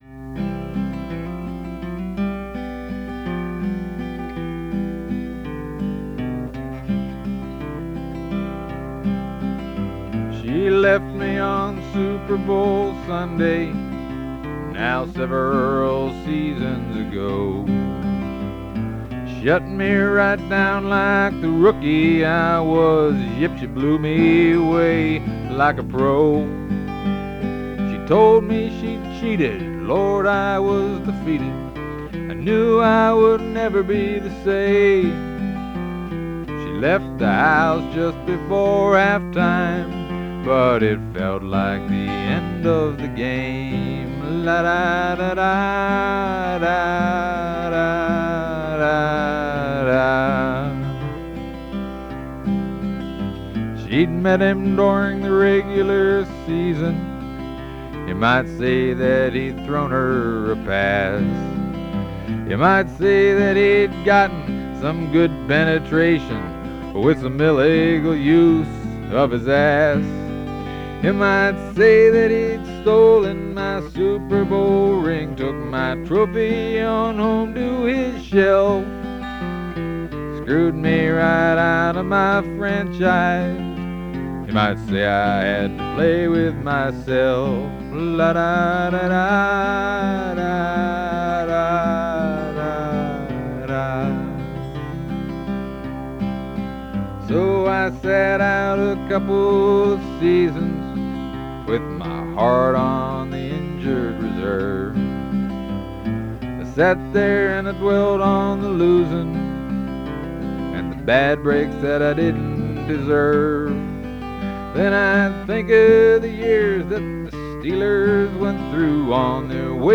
(cassette version)